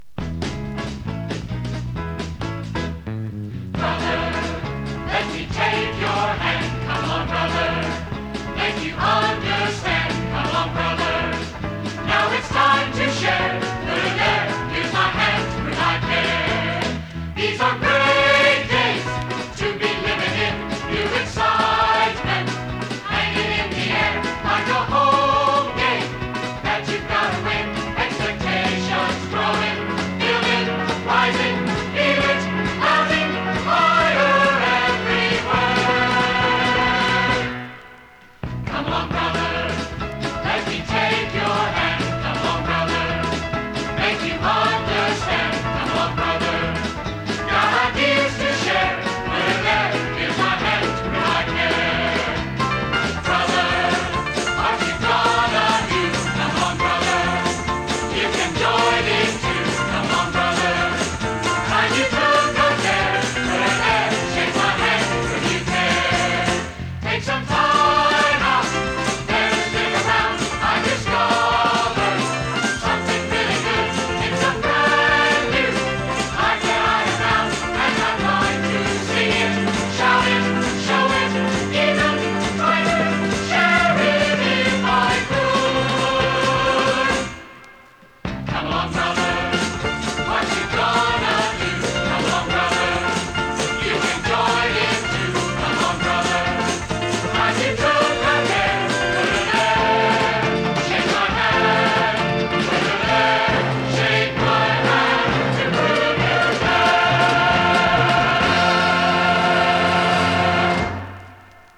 ＊稀に軽いチリ/パチ・ノイズ。